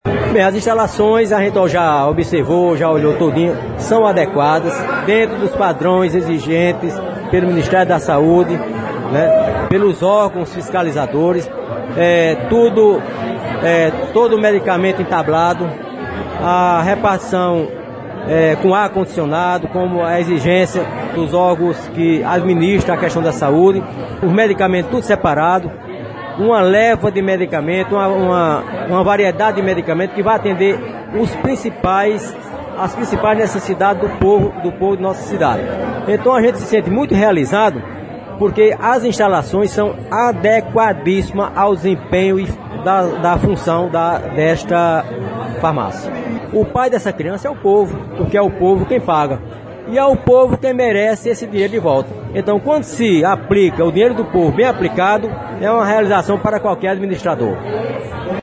O prédio totalmente reformado possui uma área de 150 metros quadrados e recebeu equipamentos novos com climatização eficiente.
PREFEITO EM EXERCÍCIO – BONIFÁCIO ROCHA –